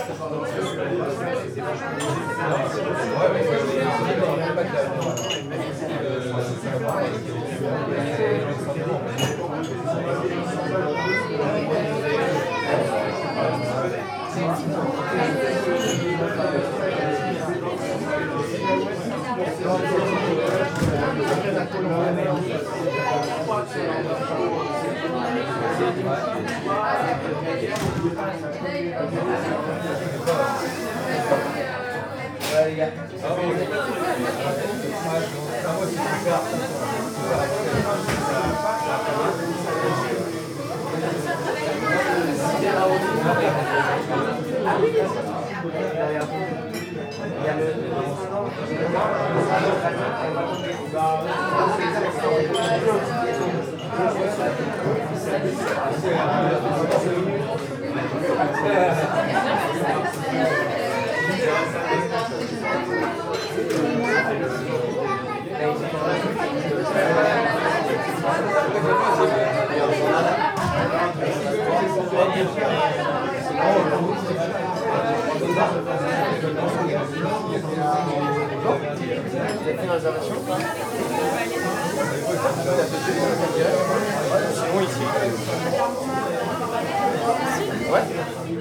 Restaurant #3
Soundscape in a restaurant during brunch. About 40 people.
UCS Category: Ambience / Restaurant & Bar (AMBRest)
Type: Soundscape
Channels: Stereo
Disposition: ORTF
Conditions: Indoor
Realism: Realistic
Equipment: SoundDevices MixPre-3 + Neumann KM184